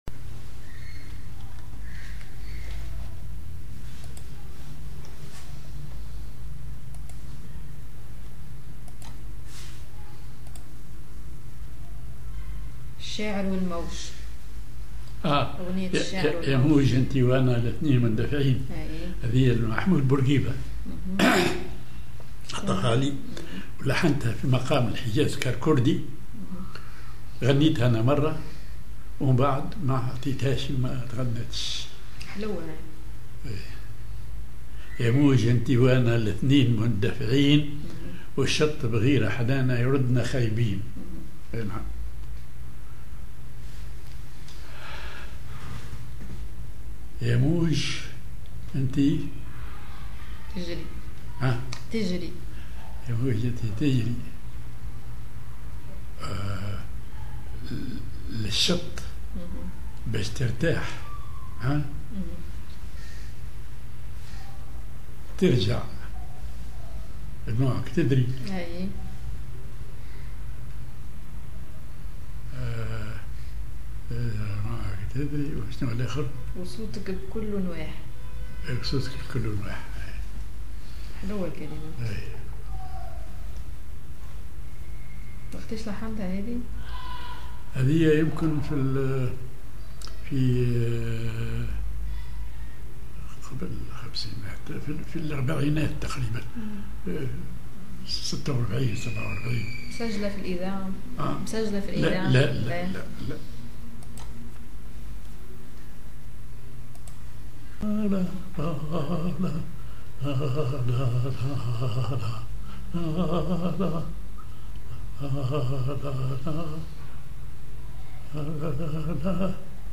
Maqam ar الحجازكار كردي
genre أغنية